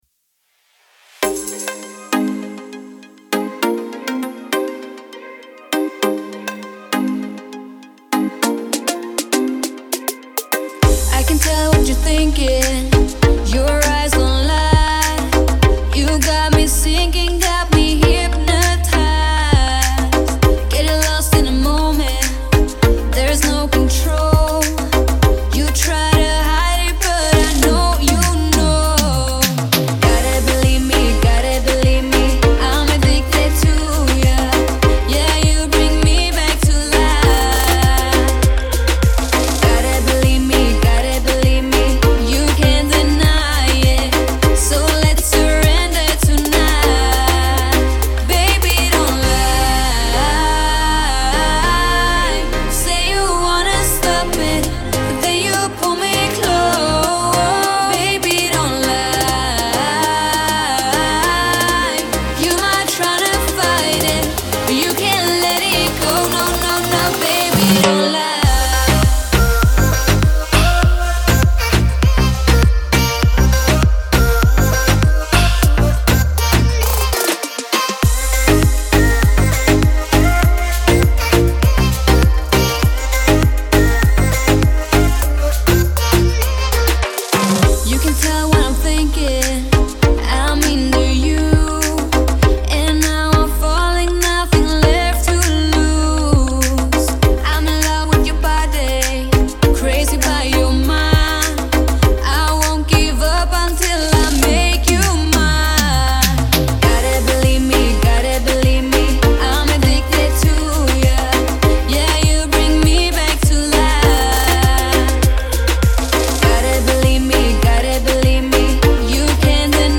поп-песня
мощный вокал